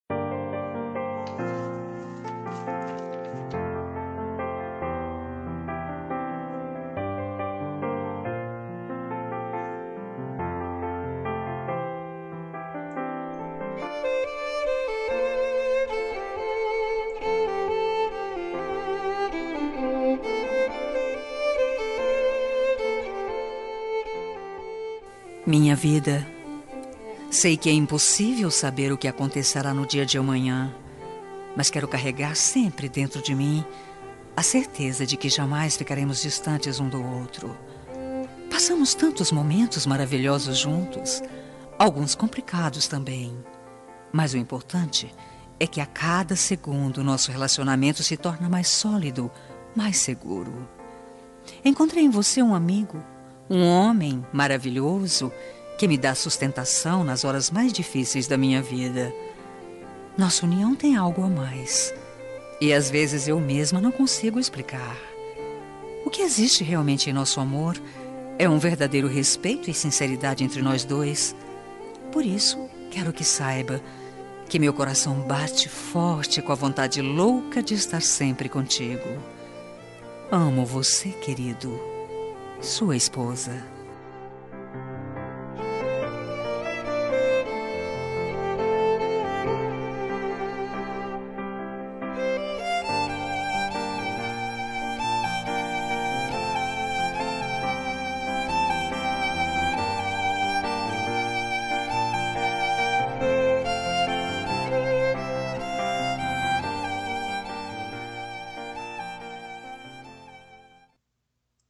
Romântica para Marido- Voz Feminina – Cód: 6722